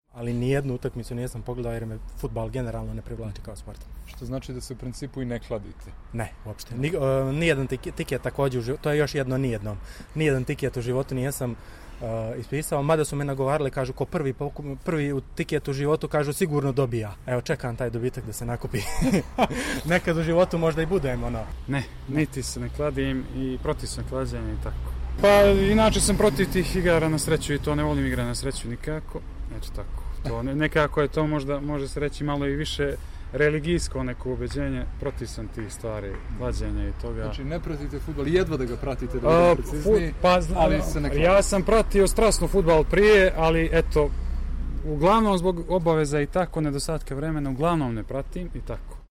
Anketa - Protivnici kladionica